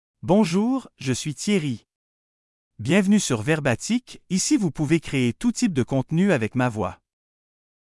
MaleFrench (Canada)
Thierry is a male AI voice for French (Canada).
Voice sample
Male
Thierry delivers clear pronunciation with authentic Canada French intonation, making your content sound professionally produced.